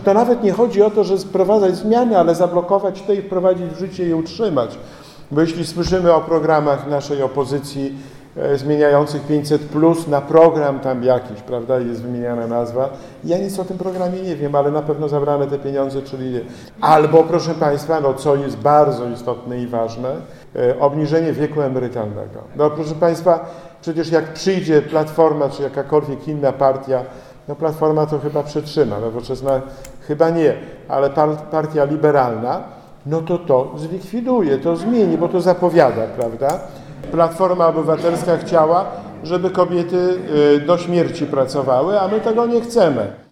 Podczas spotkania w Ełku Marszałek tłumaczył, dlaczego PiS powinien utrzymać się u władzy. Przy okazji nie zabrakło ataków na opozycję.